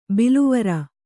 ♪ biluvara